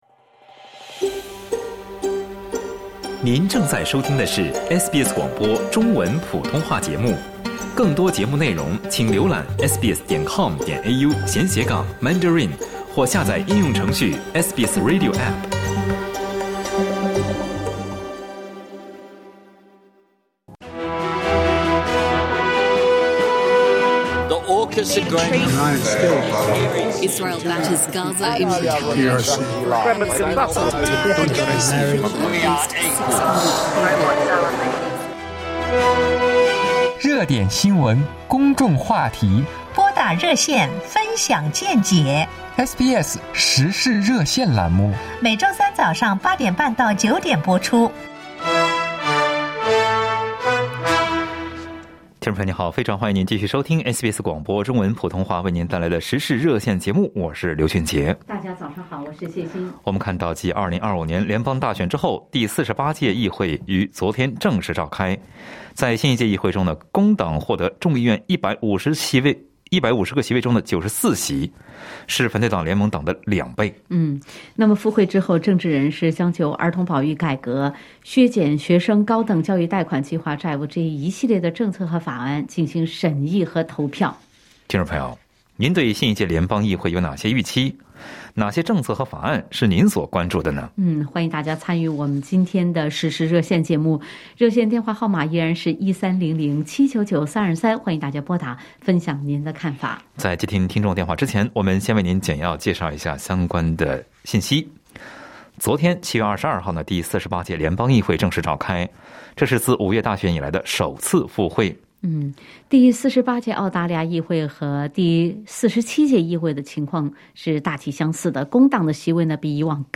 在本期《时事热线》节目中，听友们各自分享了对新一届联邦议会的预期。